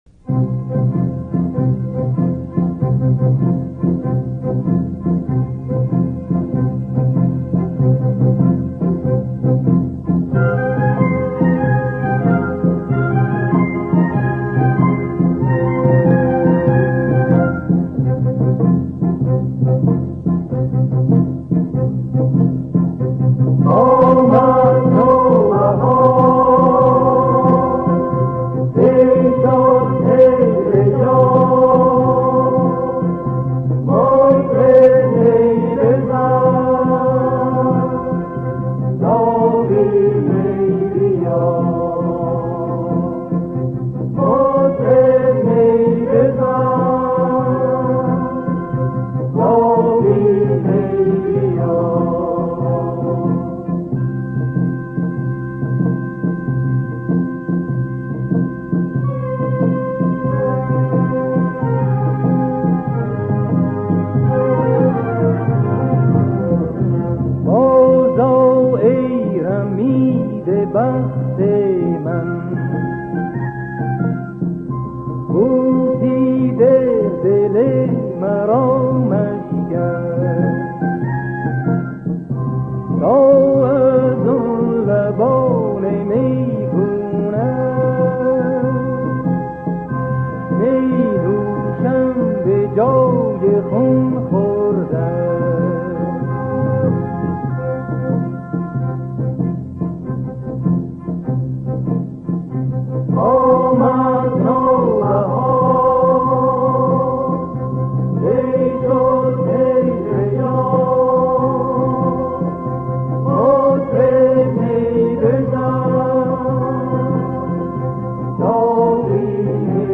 دستگاه: آواز اصفهان